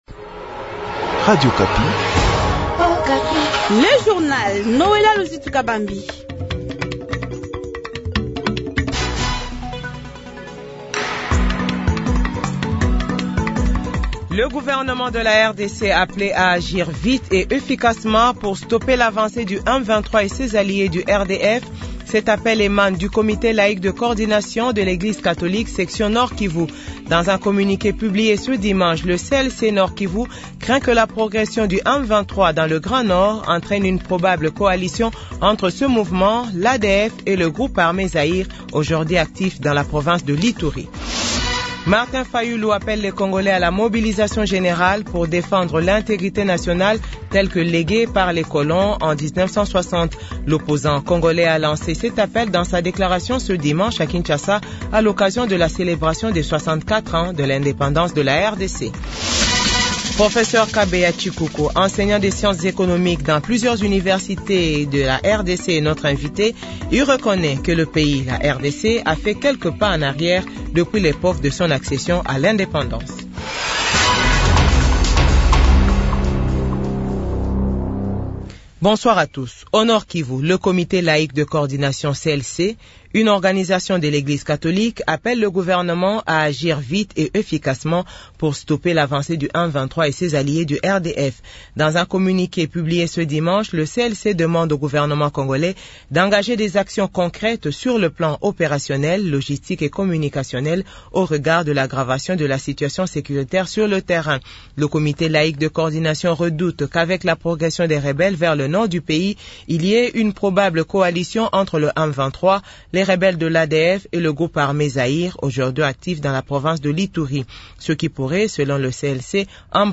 JOURNAL FRANÇAIS 18H00